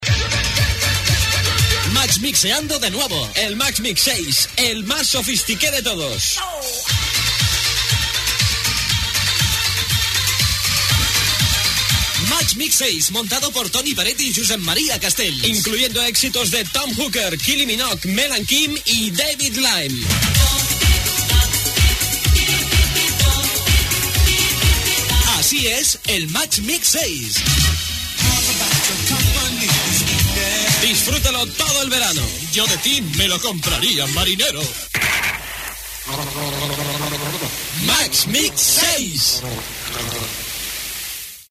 Publicitat del Max Mix 6 Gènere radiofònic Publicitat